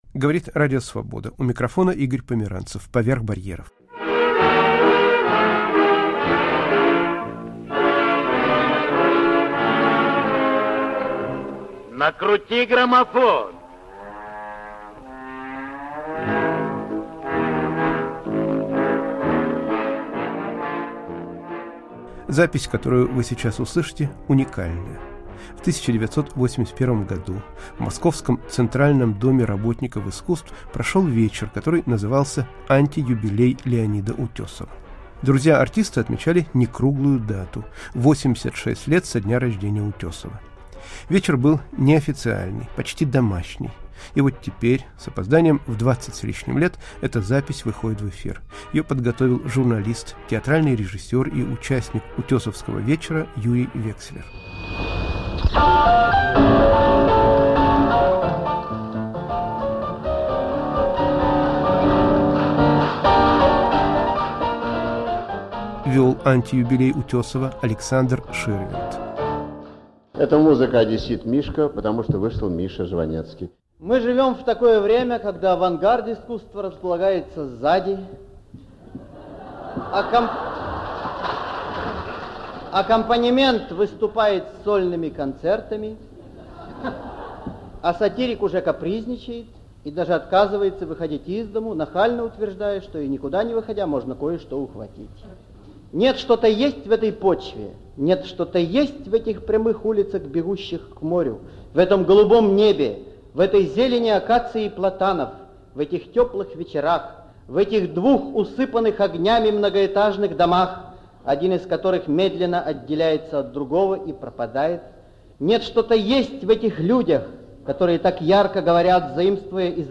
Друзья артиста отмечают в Москве его 86-летие